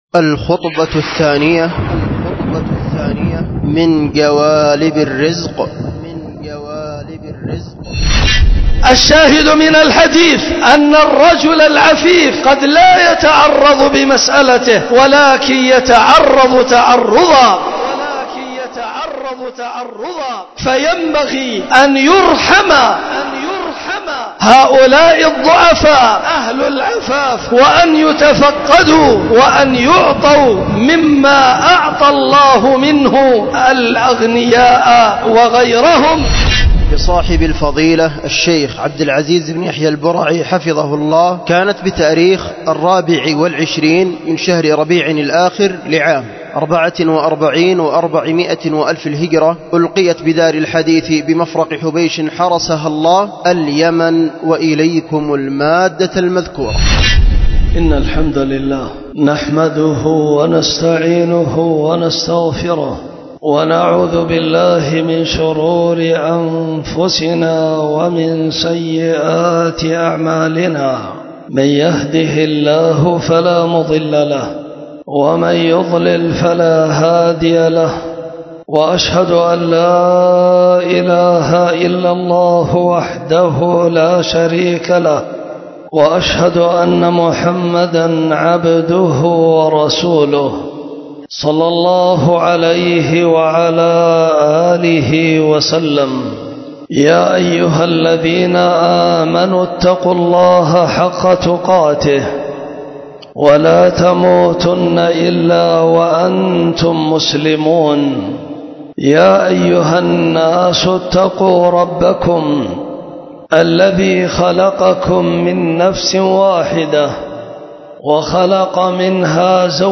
الخطبة الثانية – جوالب الرزق 24 ربيع الآخر 1444
ألقيت بدار الحديث بمفرق حبيش